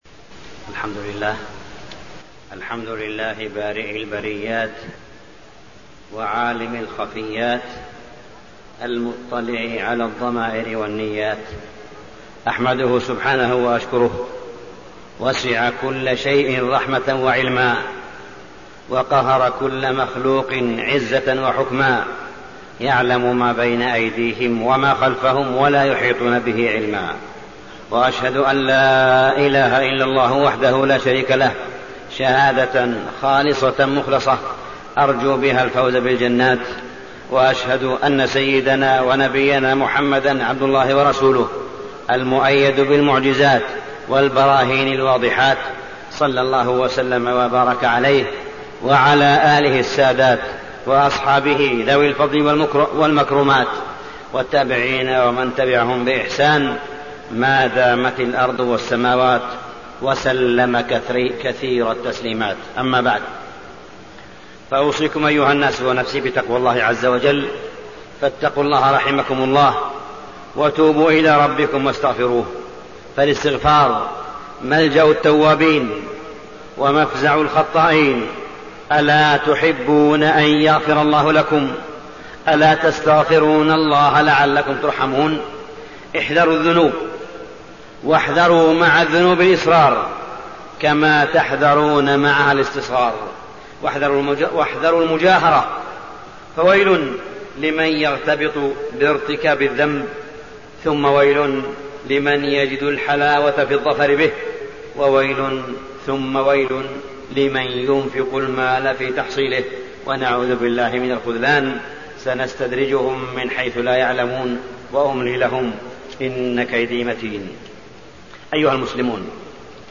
تاريخ النشر ١٤ جمادى الآخرة ١٤٣١ هـ المكان: المسجد الحرام الشيخ: معالي الشيخ أ.د. صالح بن عبدالله بن حميد معالي الشيخ أ.د. صالح بن عبدالله بن حميد الرفق